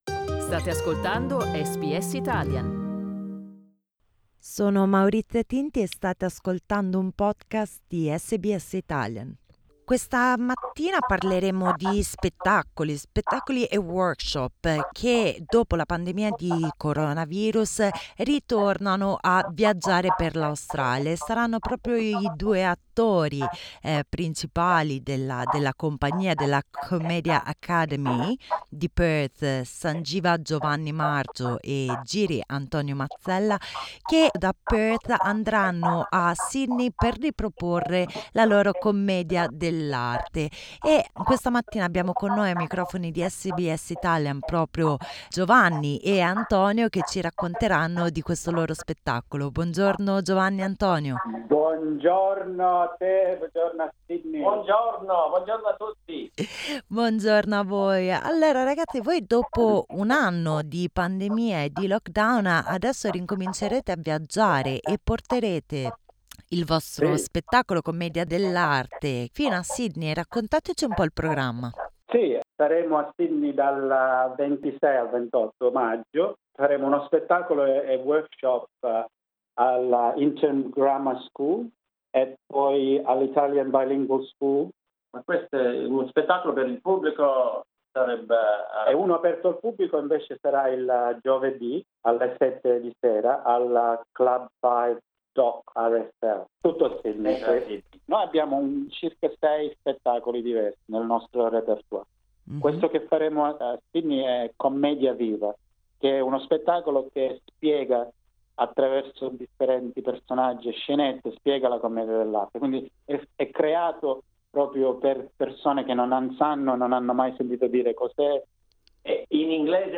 Ascolta l'intervista LISTEN TO Commedia Viva, il teatro torna a viaggiare SBS Italian 18:08 Italian Le persone in Australia devono stare ad almeno 1,5 metri di distanza dagli altri.